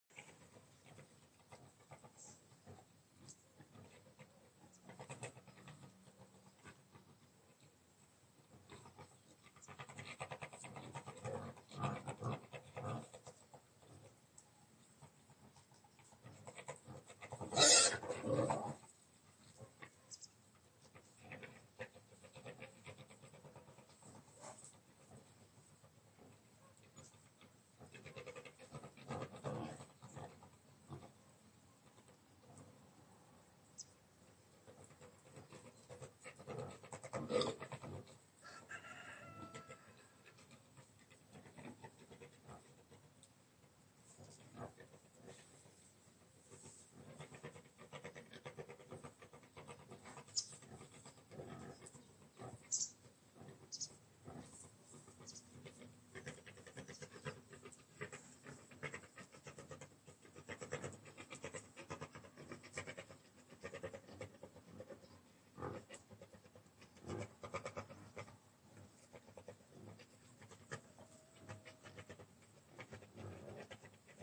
cochons
描述：With a LG G6 inside recorder softwarewas recorded in Italia in a farm near Pescara in setpember 2018
标签： pic pigs cochon farm
声道立体声